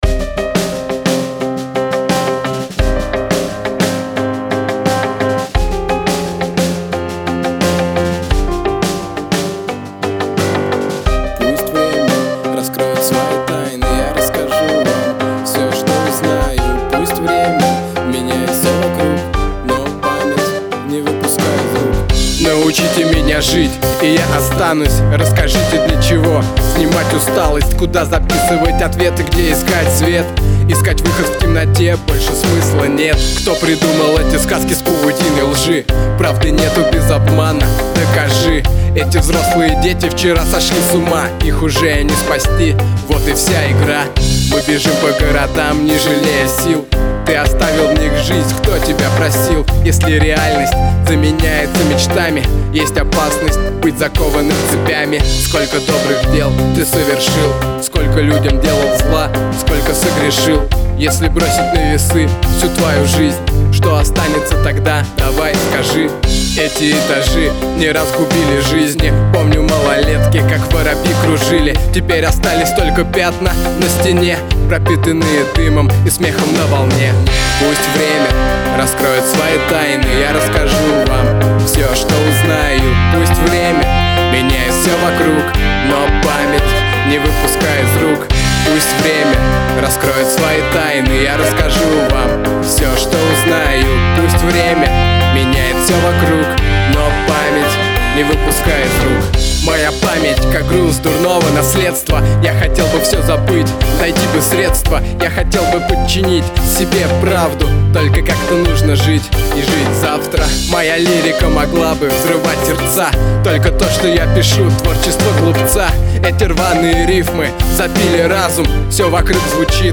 Русский Rap - авторское творчество